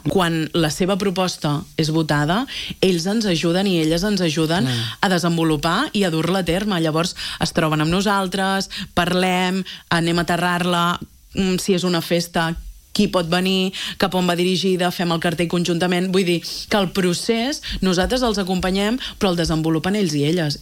La regidora de Joventut i Participació, Mariceli Santarén, n’ha parlat a l’entrevista del matinal de RCT.